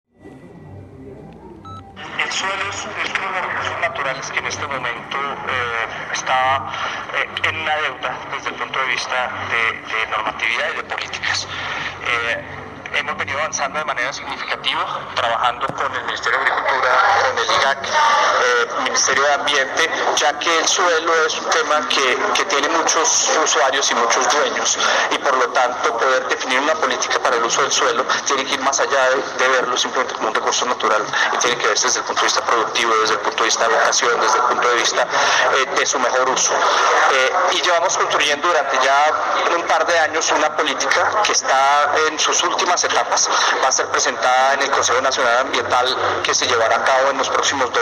Declaraciones de Pablo Vieira, viceministro del Ministerio de Ambiente y Desarrollo Sostenible